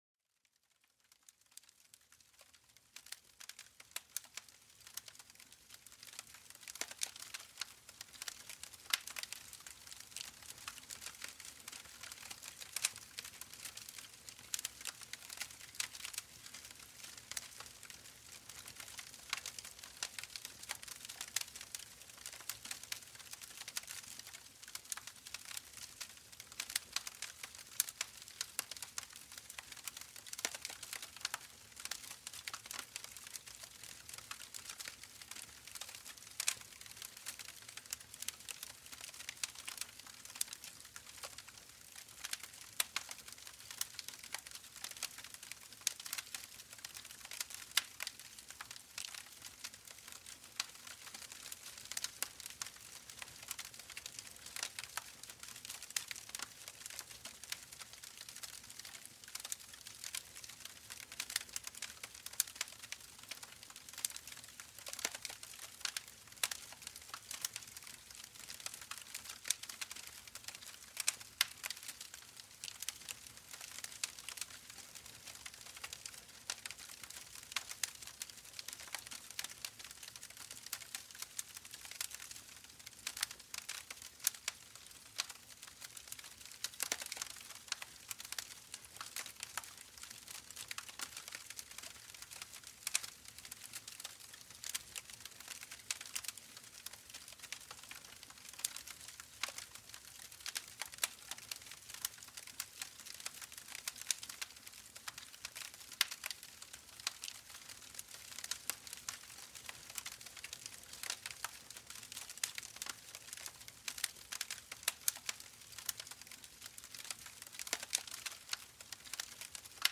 Découvrez la danse hypnotique des flammes et le crépitement réconfortant du feu.Laissez la chaleur enveloppante et les sons du feu nourrir votre âme et apaiser votre esprit.Plongez dans une expérience sensorielle unique, où le feu devient une source de tranquillité et de réconfort.Ce podcast est une expérience audio immersive qui plonge les auditeurs dans les merveilleux sons de la nature.